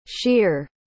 Como se pronuncia sheer em inglês?